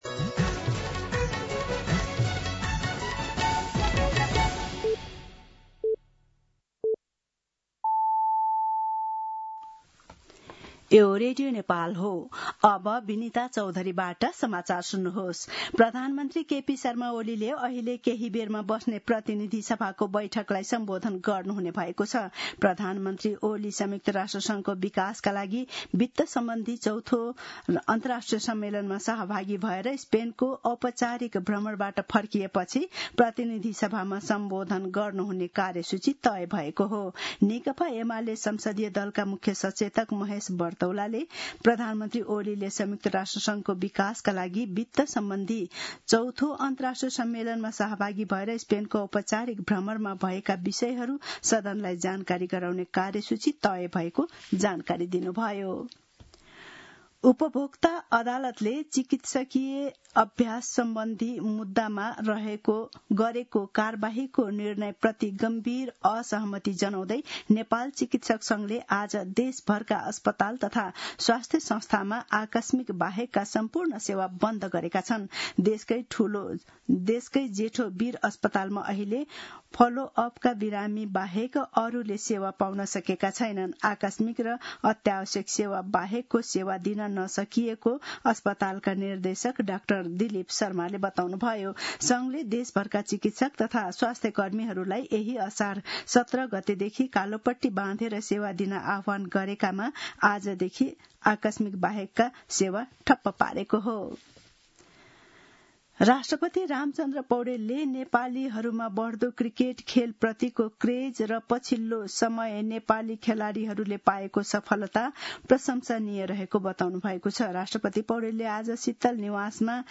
दिउँसो १ बजेको नेपाली समाचार : २३ असार , २०८२